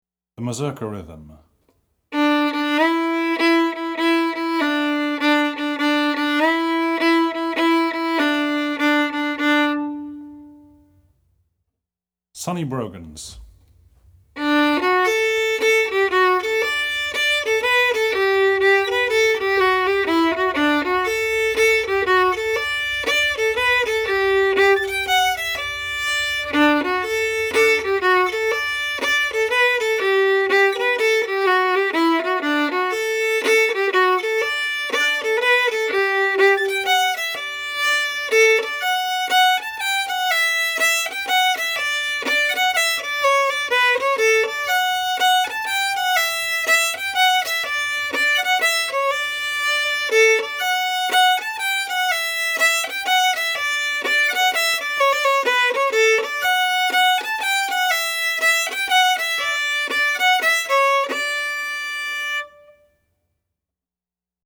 DIGITAL SHEET MUSIC - FIDDLE SOLO